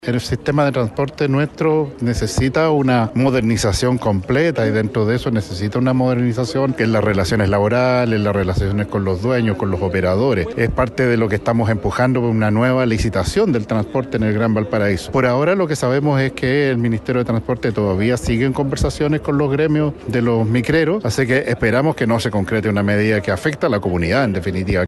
Desde el Gobierno, el delegado presidencial de Valparaíso, Yanino Riquelme, valoró la disposición al diálogo y aseguró que se están haciendo los esfuerzos para cumplir los compromisos adquiridos.